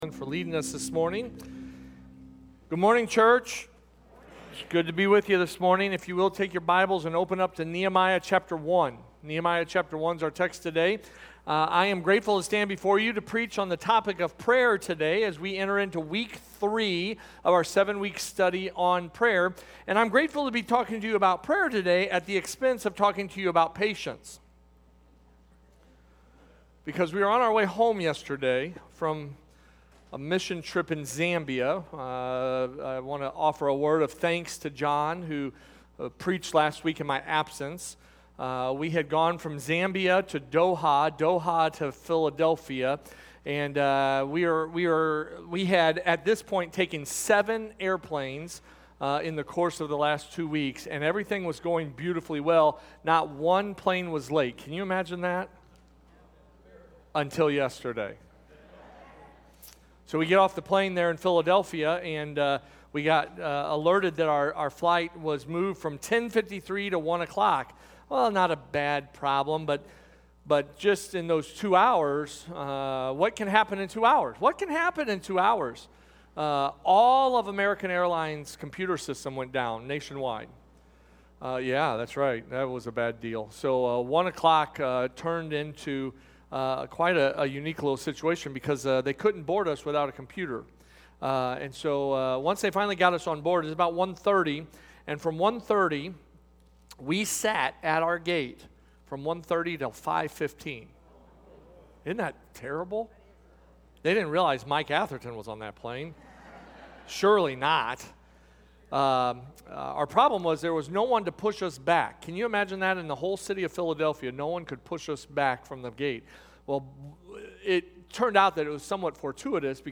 A message from the series "Lord Teach Us To Pray."